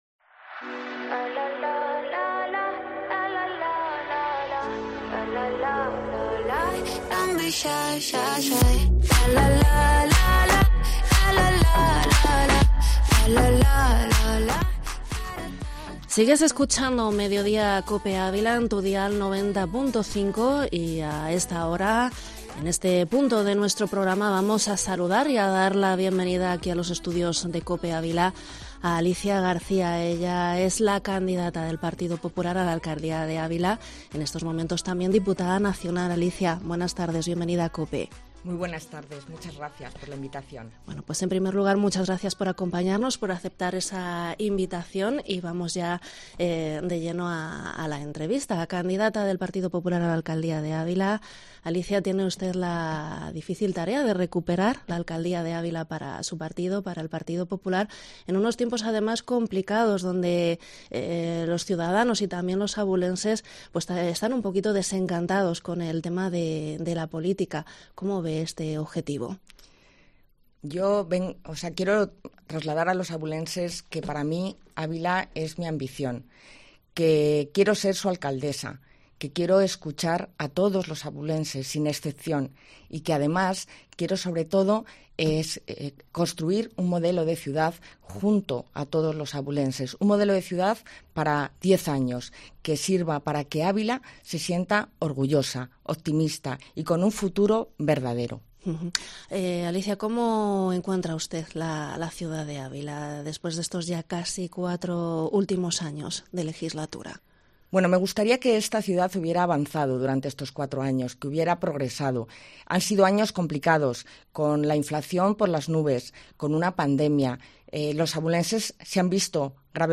ENTREVISTA
Entrevista a Alicia García, candidata del PP a la alcaldía de Ávila en Mediodía Cope